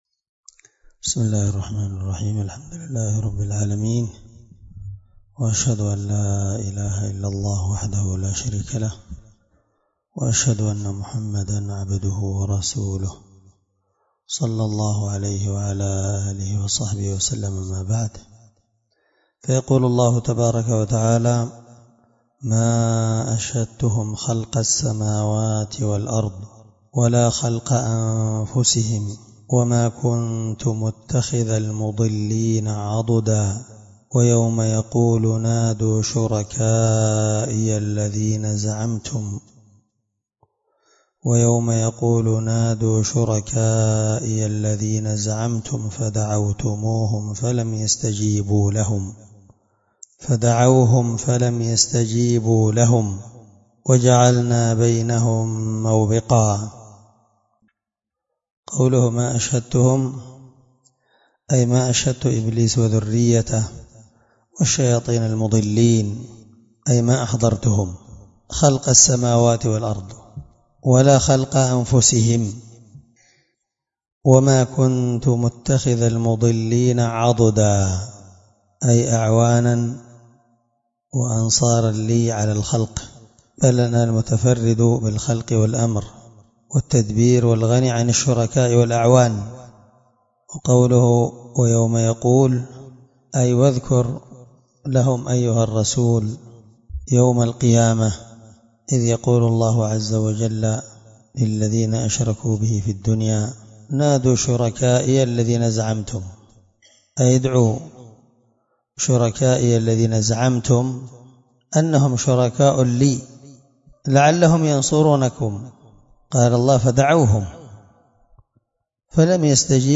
الدرس19 تفسير آية (51-52) من سورة الكهف